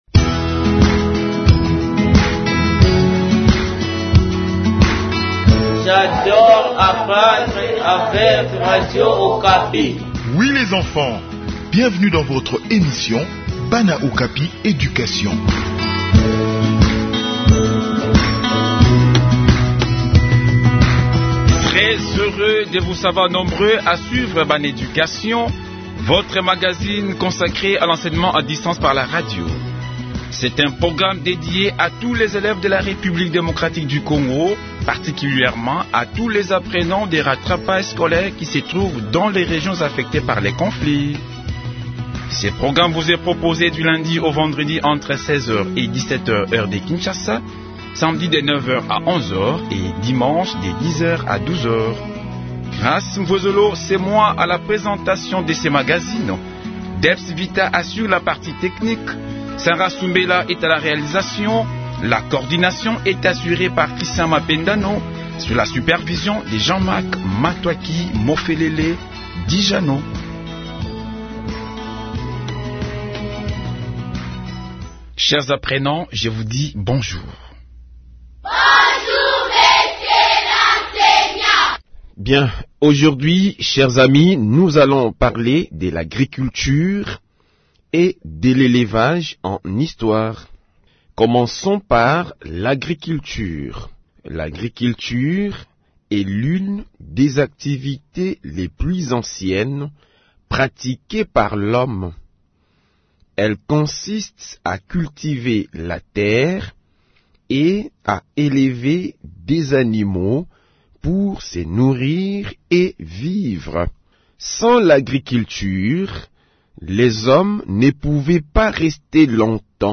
Enseignement à distance: leçon d’histoire sur l’agriculture